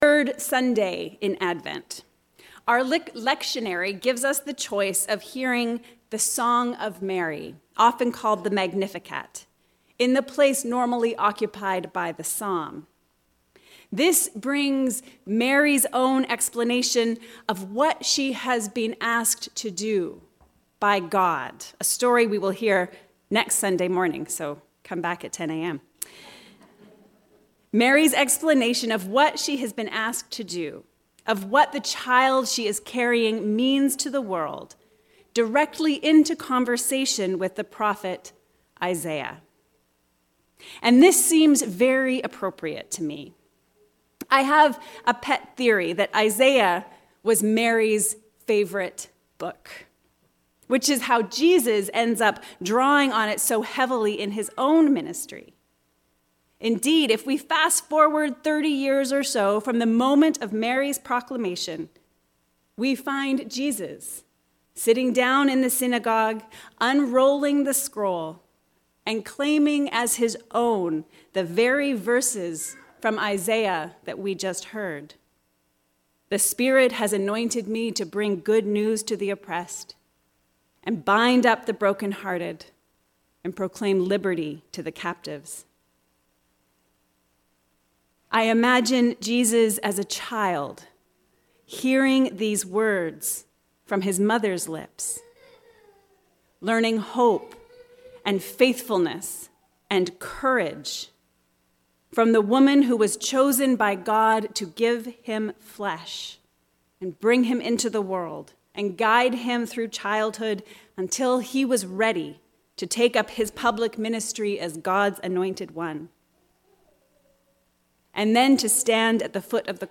From Isaiah to Mary to Jesus to Us. A sermon for the 3rd Sunday in Advent